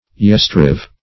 Search Result for " yestereve" : The Collaborative International Dictionary of English v.0.48: Yestereve \Yes"ter*eve`\, Yester-evening \Yes"ter-e`ven*ing\, n. The evening of yesterday; the evening last past.
yestereve.mp3